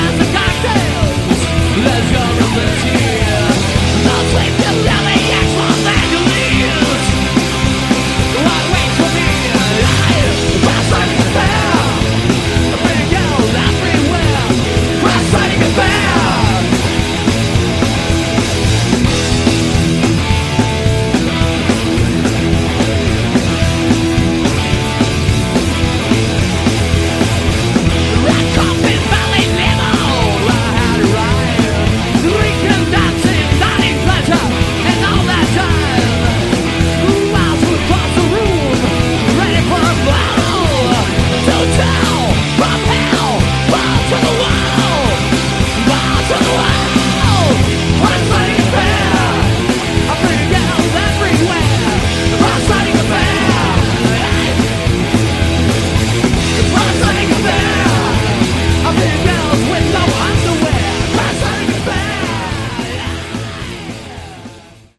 Category: Glam
guitar
vocals
bass
drums